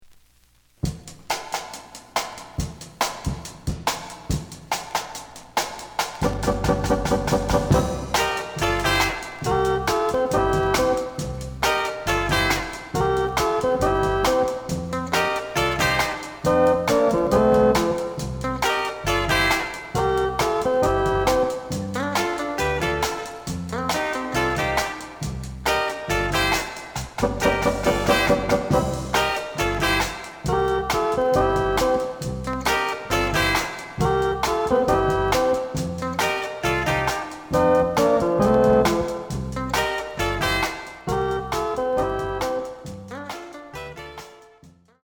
The audio sample is recorded from the actual item.
●Format: 7 inch
●Genre: Latin